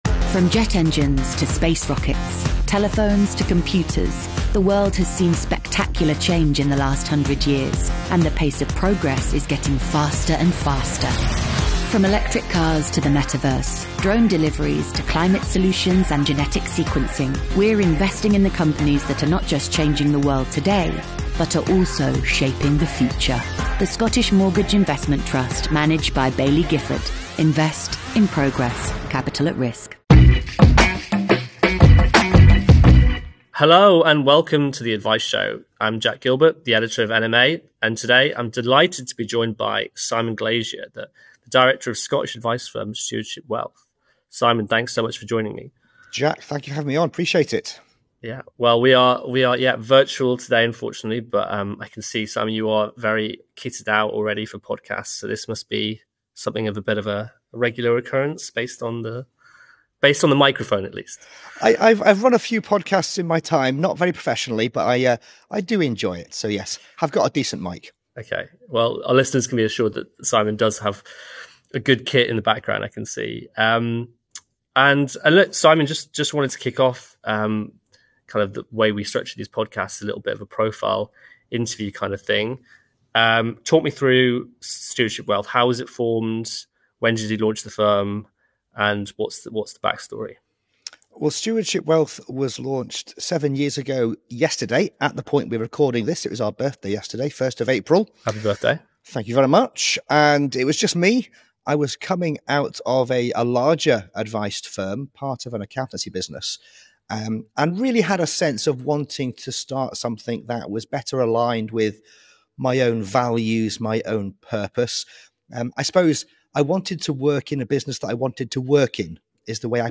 Top 100 interviews